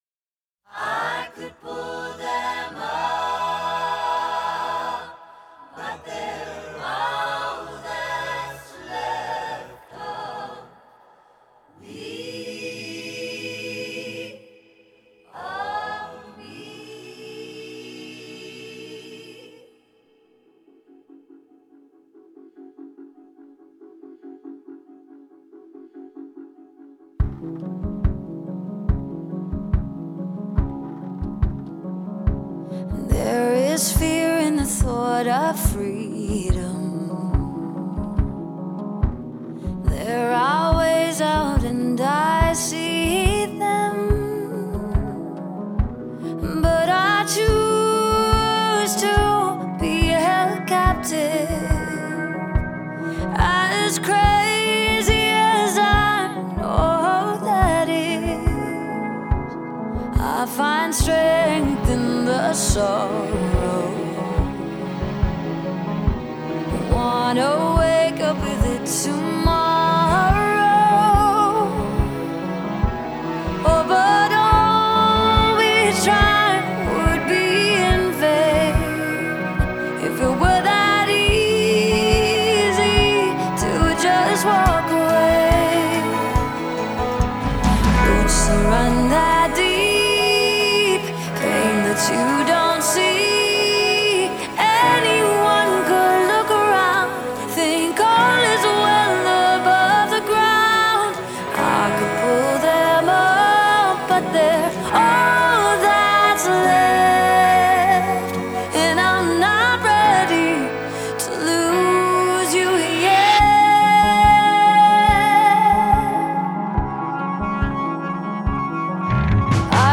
Genre: pop, female vocalists, singer-songwriter